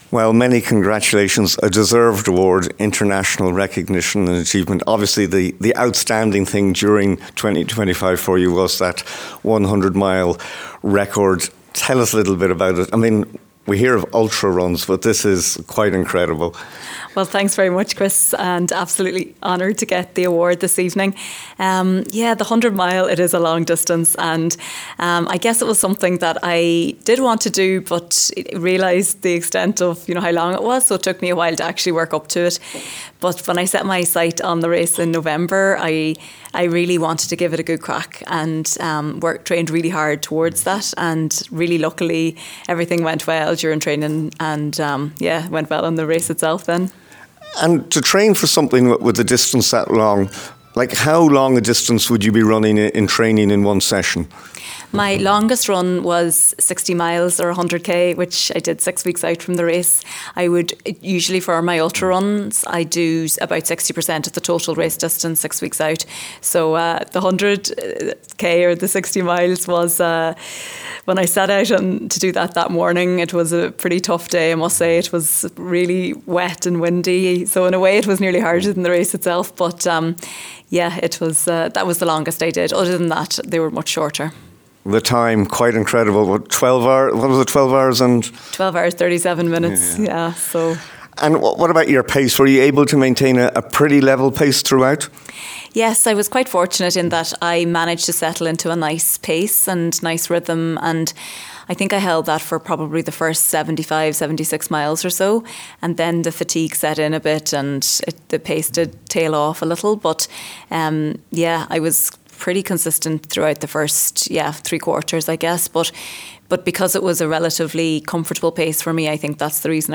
spoke to her after the awards ceremony…